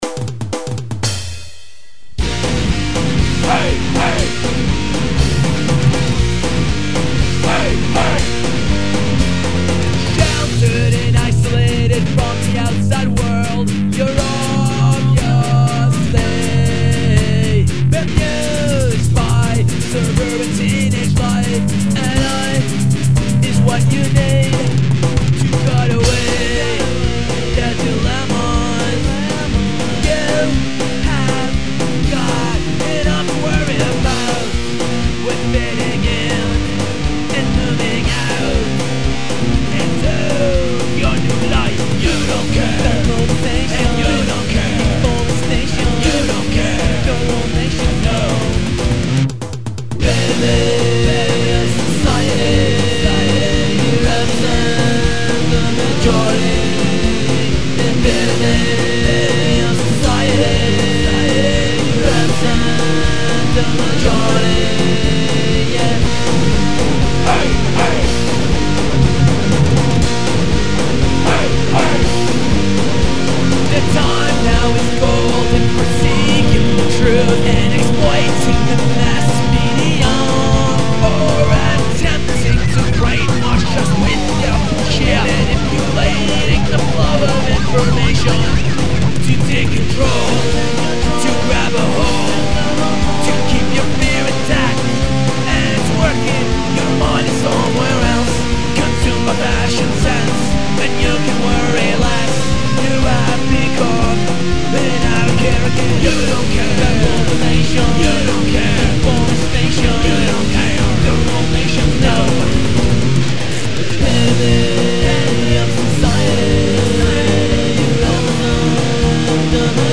Original Music